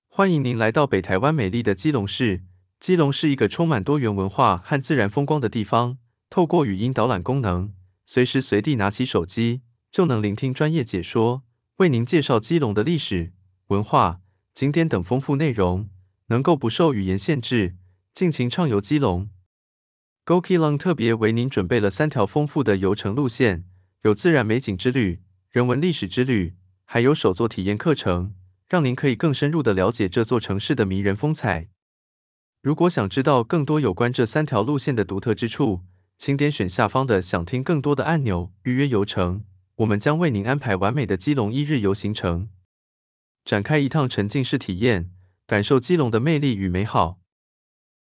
GO KEELUNG沉浸式語音導覽 遊基隆